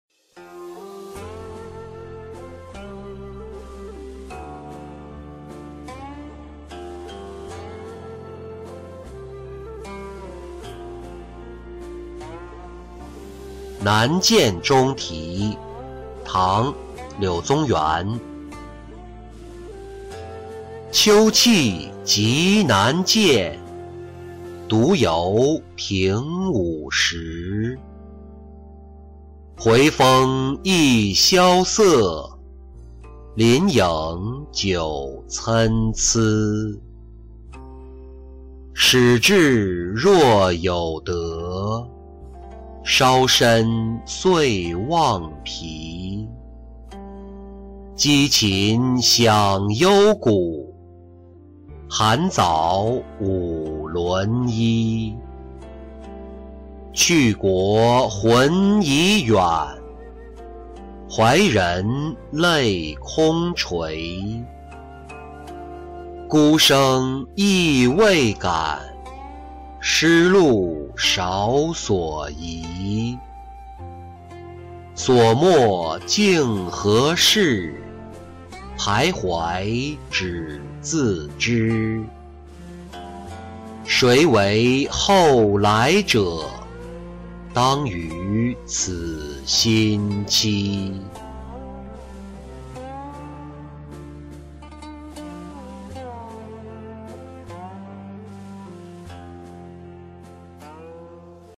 南涧中题-音频朗读